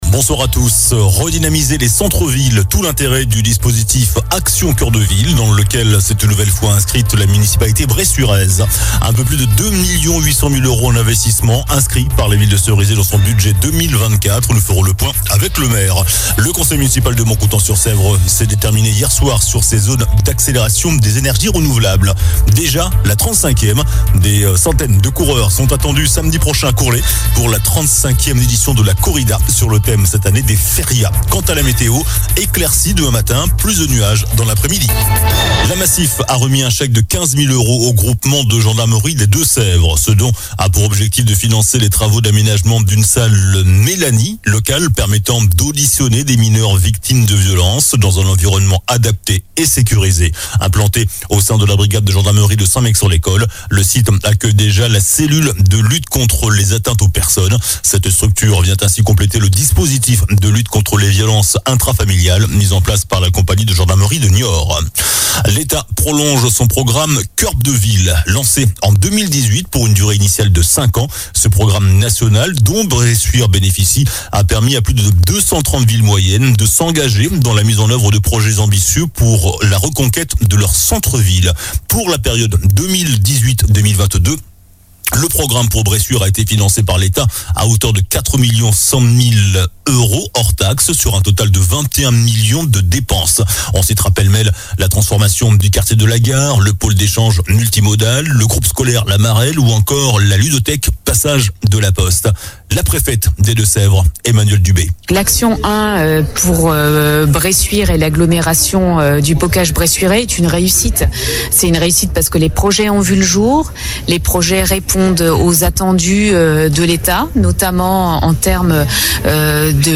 Journal du mardi 19 Décembre (soir)